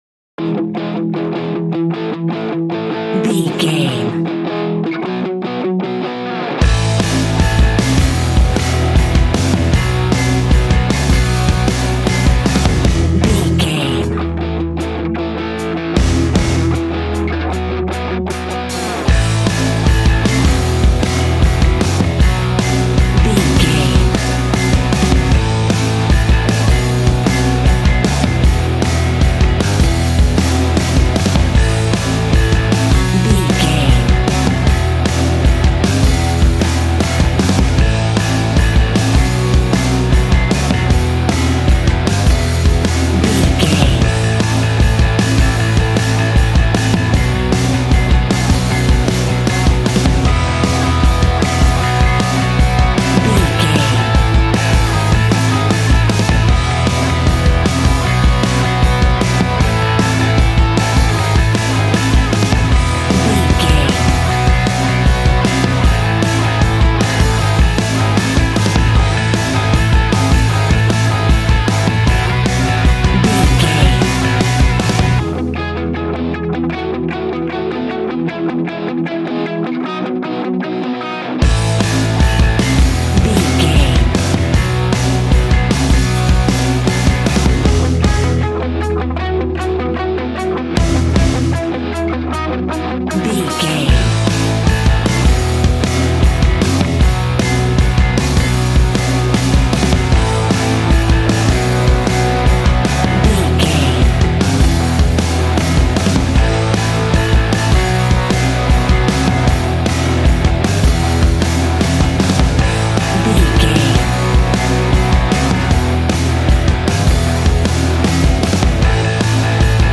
Fast paced
Ionian/Major
groovy
powerful
electric organ
drums
electric guitar
bass guitar